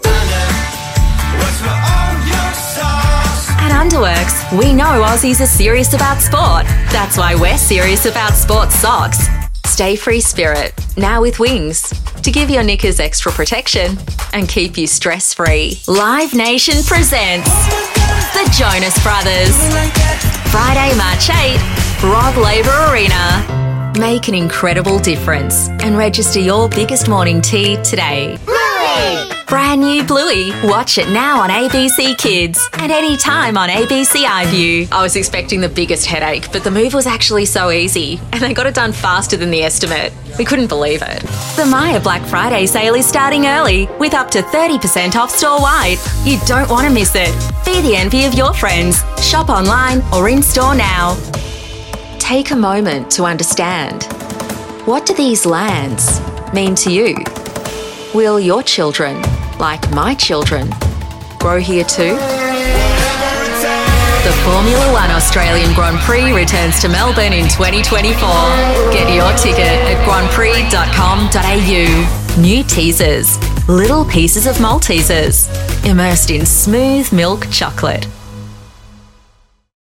Female Voice Over Talent, Artists & Actors
Yng Adult (18-29) | Adult (30-50)